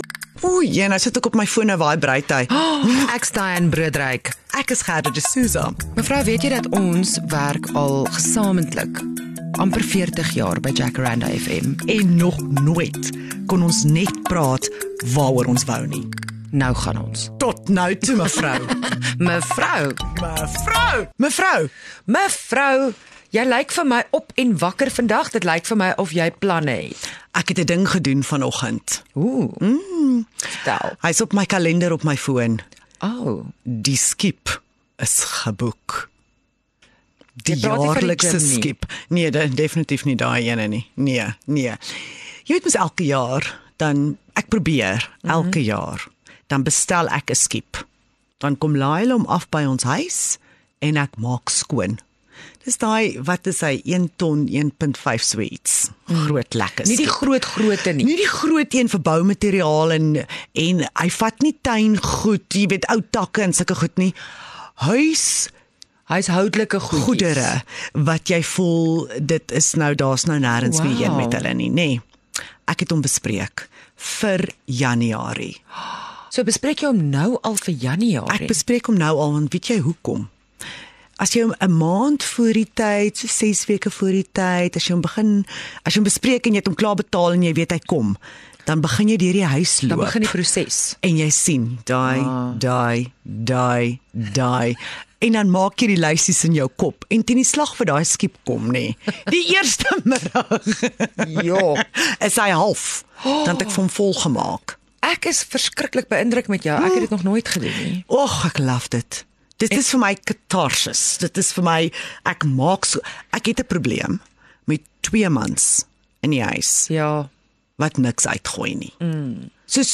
Dis hier waar jy jou ore kan uitleen aan twee internasionaal bekroonde Mevrouens se alledaagse lewens - ‘n dosis hardop lag, lang trane huil, vra vir genade, pleit vir geduld.